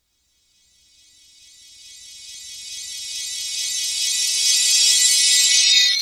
SHIMMERY.wav